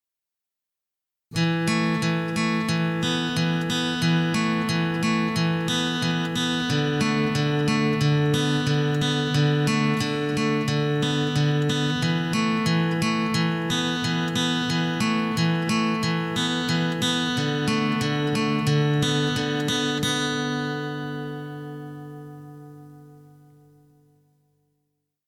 This is a sample of the intro that I did using the same technique but the tracks were dry before mixing. A touch of reverb added to the end mix... Attachments guitar.mp3 guitar.mp3 596.8 KB · Views: 70